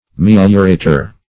Meliorater \Mel"io*ra`ter\, n.